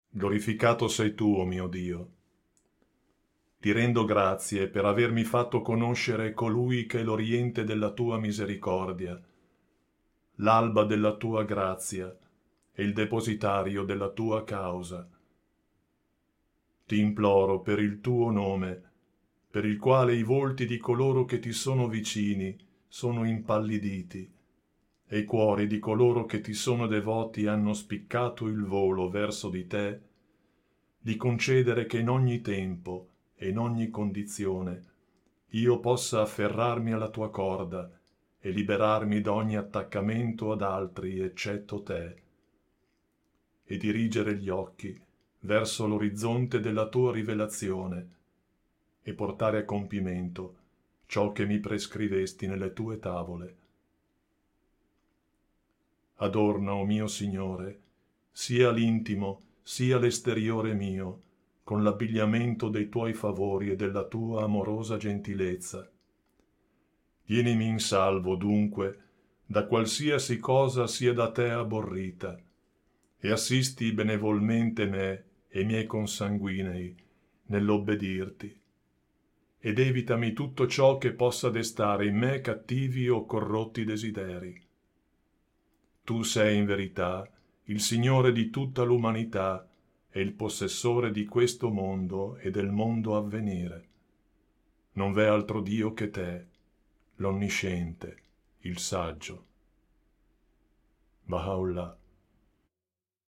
Audiolibri Bahá'í Gratis